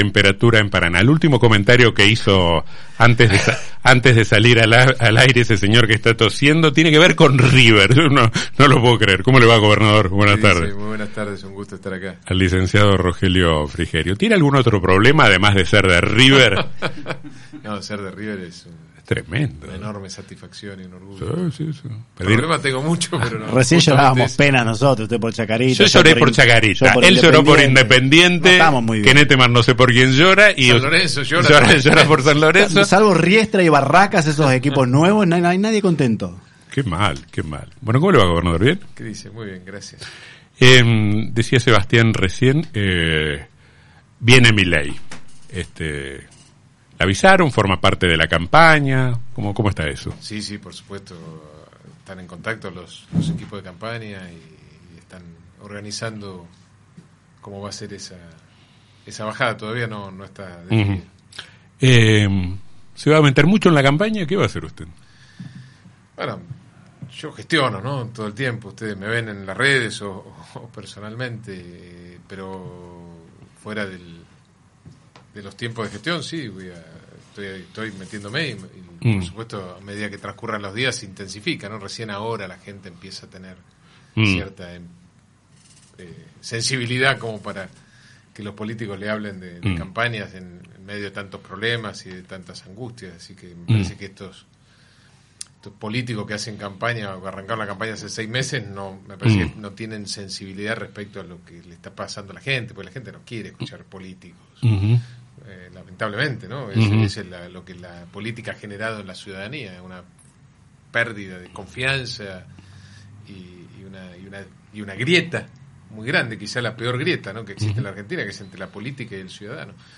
entrevista con el programa En El Dos Mil También
realizada en los estudios de Radio Costa Paraná (88.1)